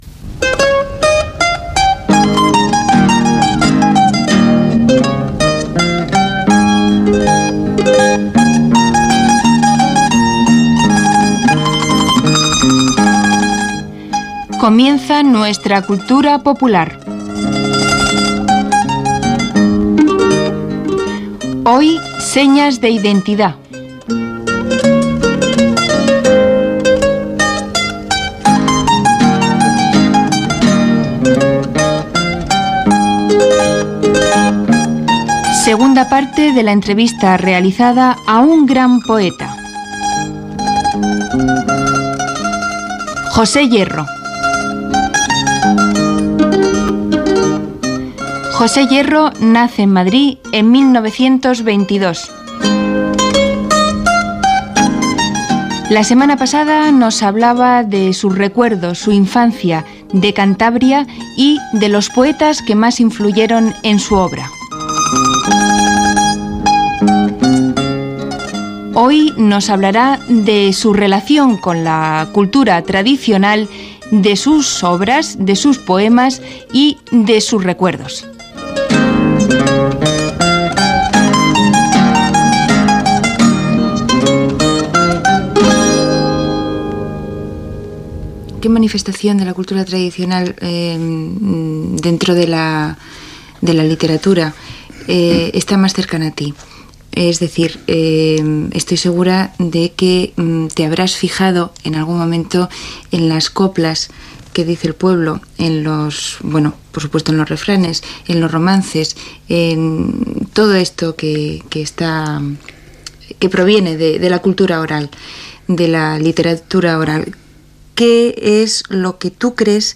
Careta del programa i espai dedicat a "Señas de identidad", segona part de l'entrevista al poeta José Hierro. S'hi parla dels romanços
Musical